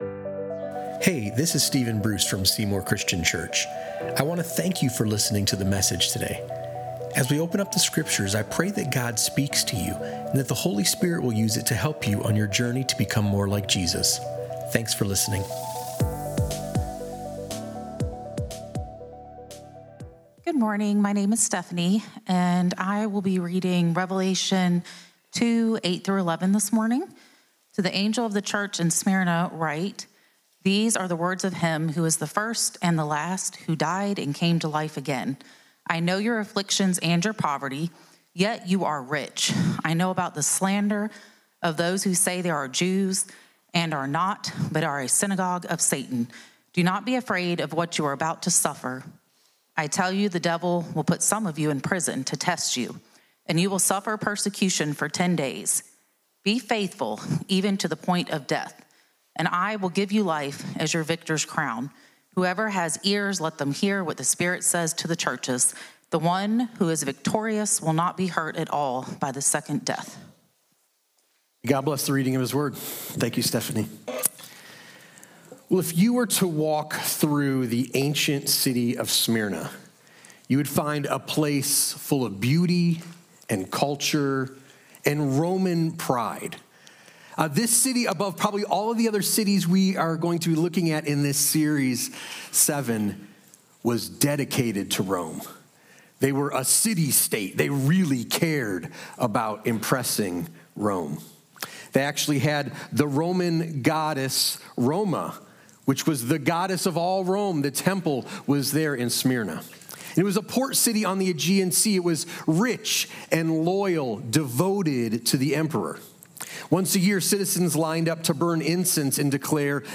Crushed But Crowned | Faith Proven Under Pressure | Sermon on Revelation 2:8-11